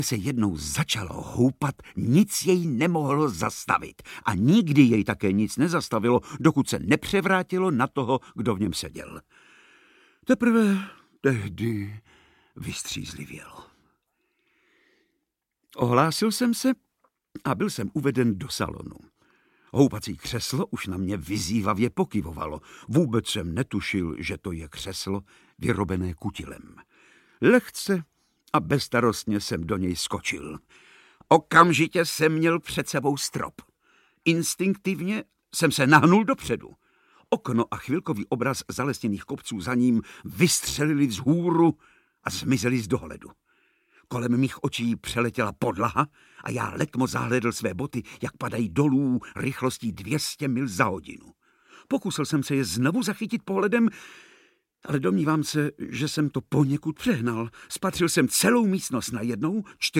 Audiobook
Read: Vladimír Brabec